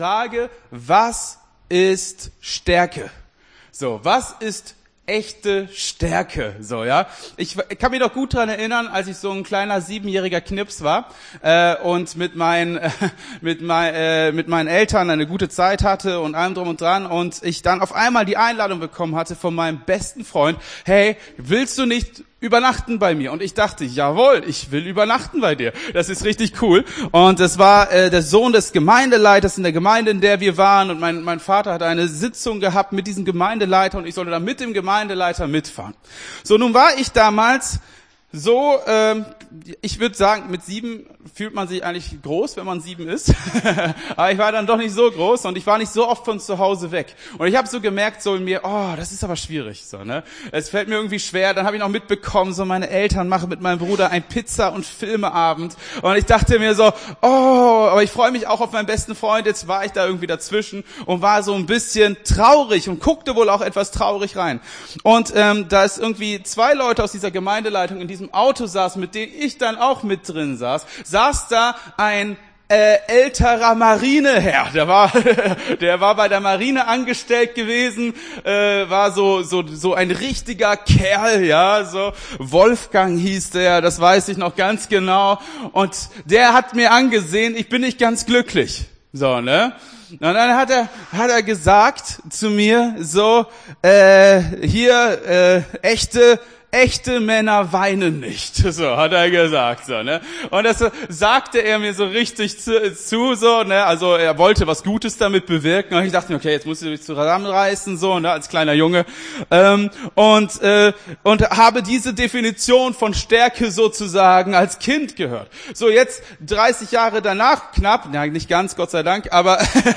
Gottesdienst 14.08.22 - FCG Hagen